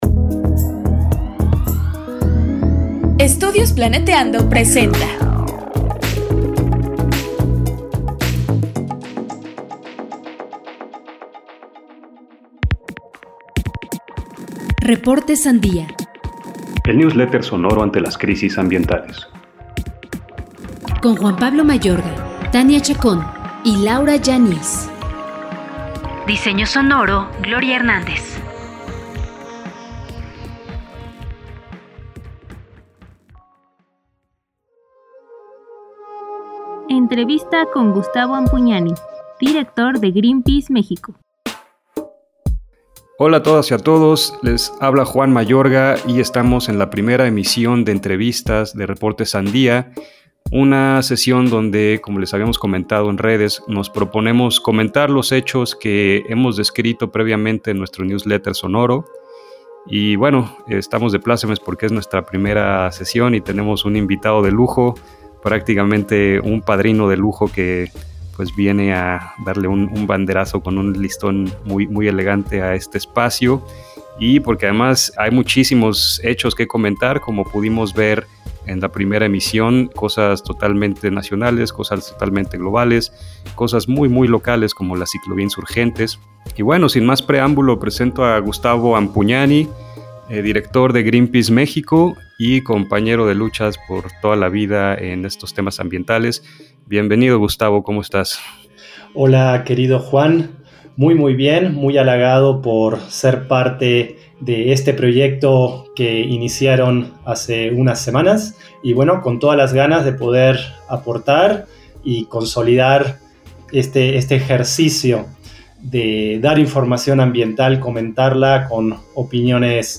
entrevista
RS-ENTREVISTA-1-mezcla.mp3